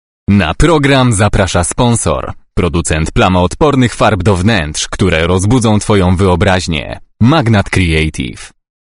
Owner of a pleasant, colorful and energetic voice that works well for announcements, commercials, narration and audiobooks.
Billboard sponsorski
02.-Billboard-sponsorski-solo-VO.mp3